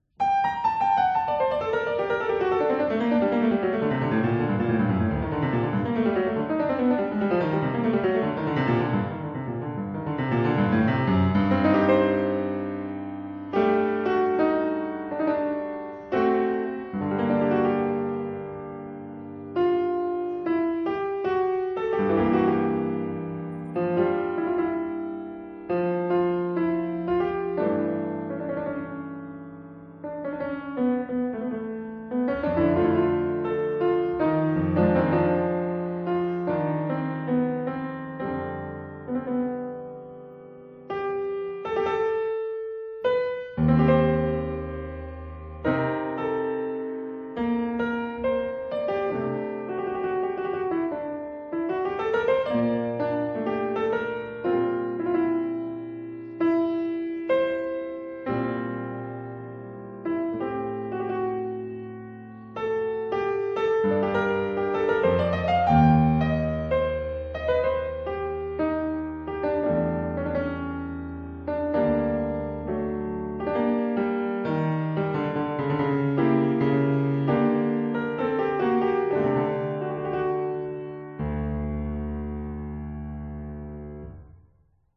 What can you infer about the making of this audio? Recorded at Fazioli Concert Hall of Sacile (PN), Italy